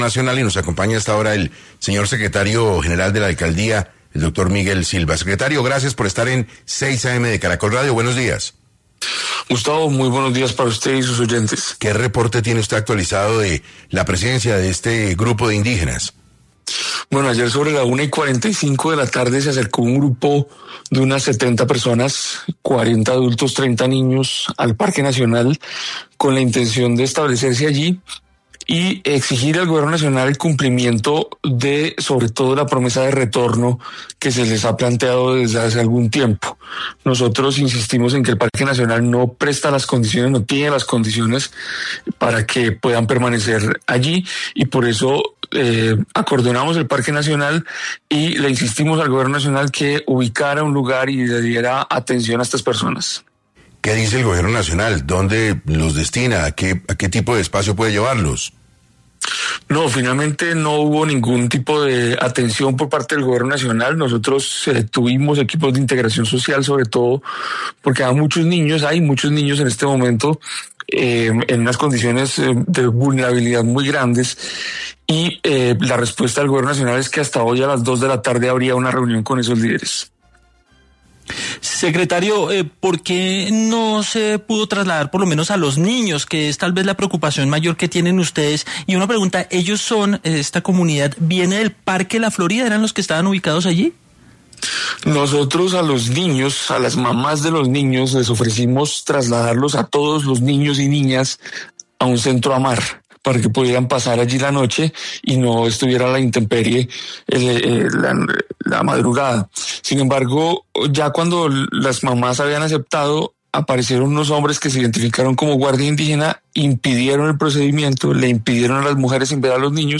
En entrevista para 6AM, el secretario General de la Alcaldía de Bogotá, Miguel Silva Moyano, quien habló sobre los nuevos retos que enfrentan en las negociaciones con la estadía de grupos indígenas en la ciudad.